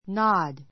nod nɑ́d ナ ド 動詞 三単現 nods nɑ́dz ナ ヅ 過去形・過去分詞 nodded nɑ́did ナ デ ド -ing形 nodding nɑ́diŋ ナ ディン ぐ うなずく, 会釈 えしゃく する; （居眠 いねむ りで） こっくりする If you understand me, nod; if you don't, shake your head.